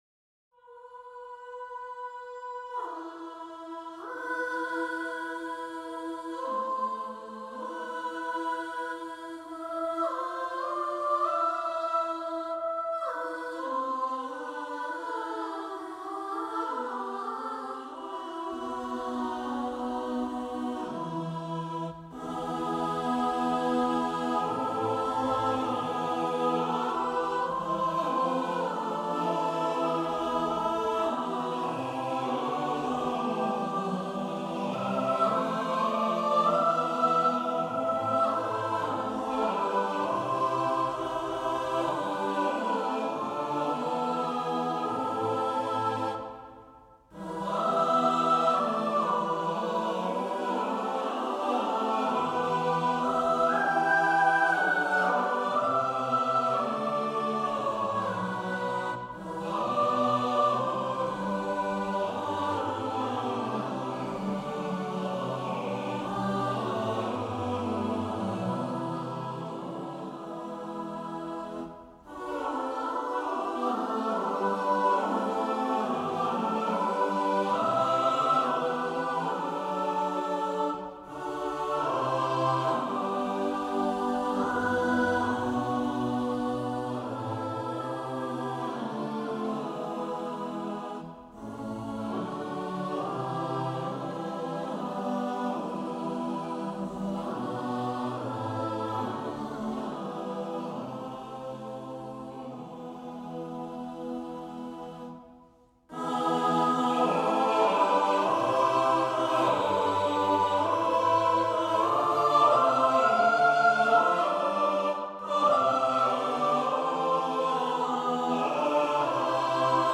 Victoria & Vaughan Willams The Thread of Destiny Koor Januari 2025 PDF